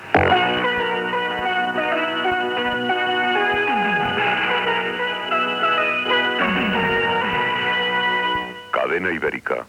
Sintonia de la cadena i identificació.